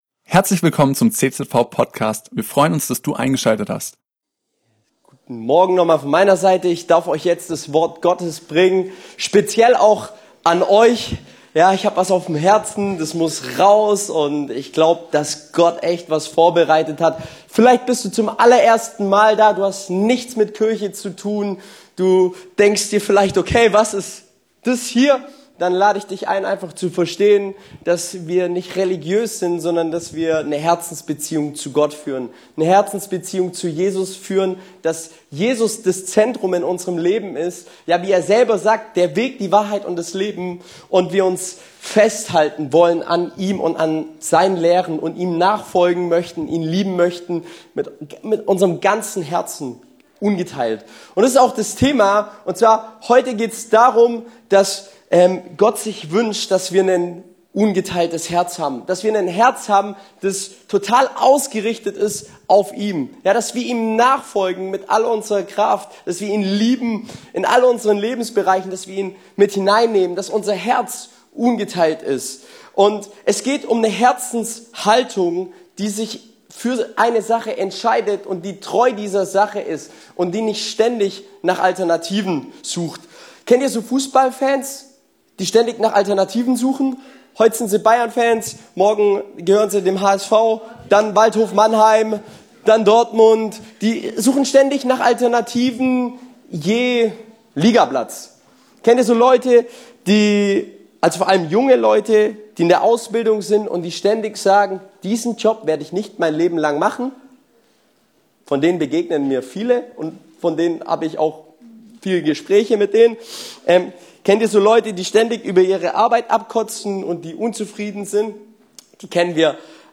im Rahmen unserer diesjährigen Teenie-Segnung über die Bedeutung eines ungeteilten Herzens.